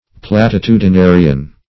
Meaning of platitudinarian. platitudinarian synonyms, pronunciation, spelling and more from Free Dictionary.
platitudinarian.mp3